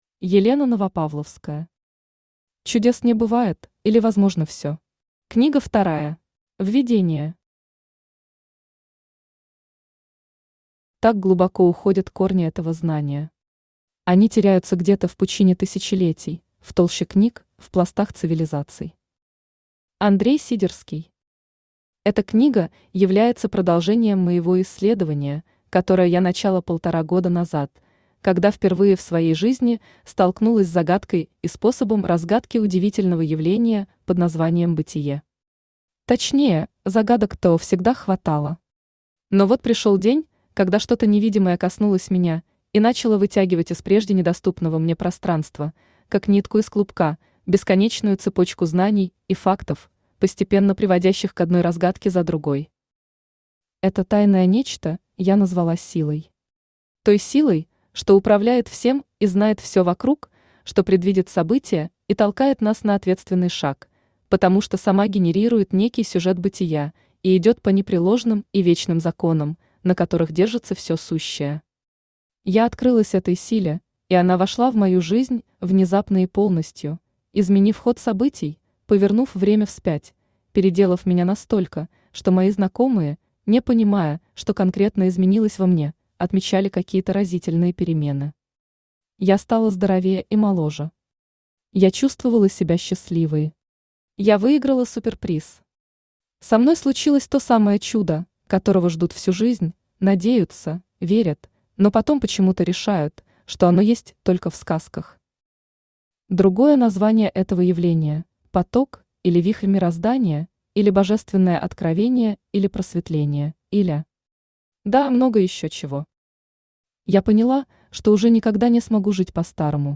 Аудиокнига Чудес не бывает, или Возможно всё. Книга вторая | Библиотека аудиокниг
Книга вторая Автор Елена Новопавловская Читает аудиокнигу Авточтец ЛитРес.